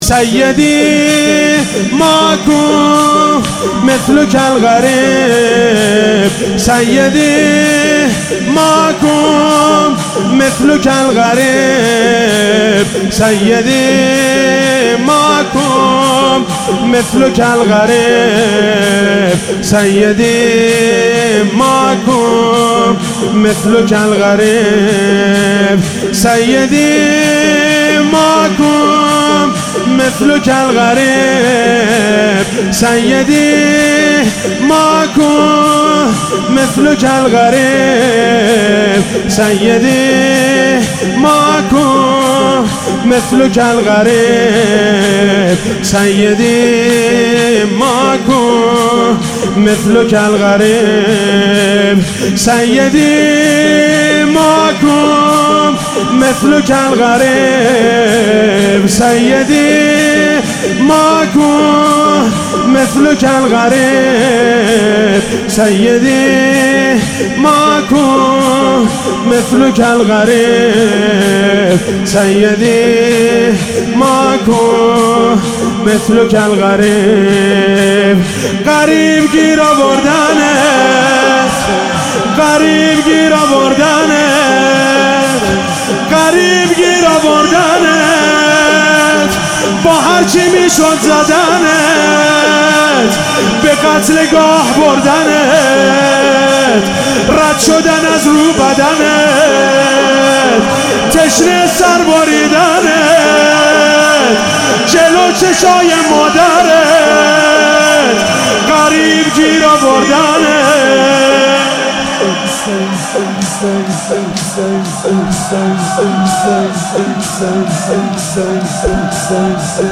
شهادت حضرت رقیه سلام الله علیها